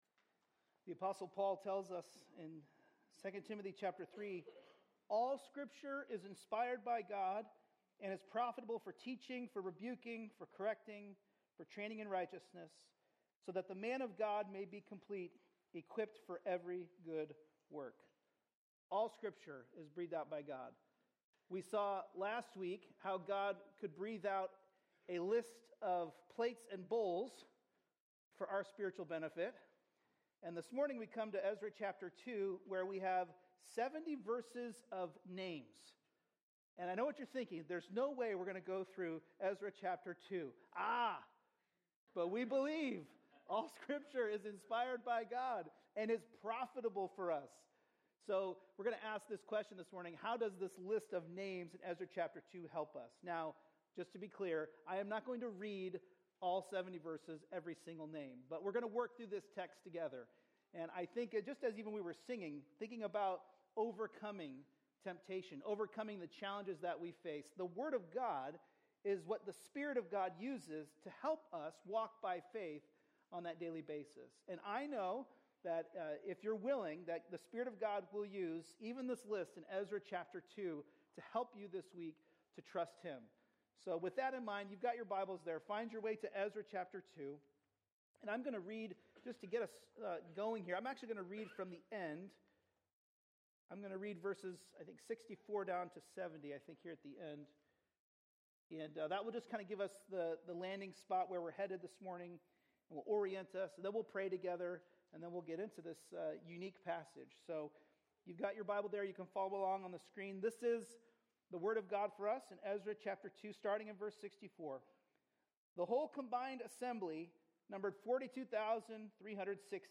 A message from the series "Ezra/Nehemiah." In Ezra 9:1-15, we learn that separation from the world is not optional, it is essential.